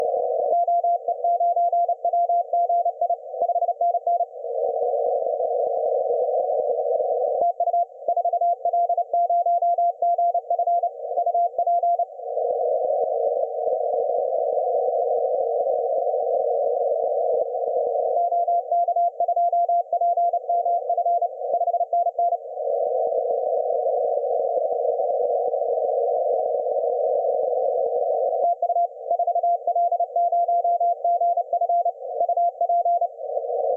4L0GF - Georgia on 20m CW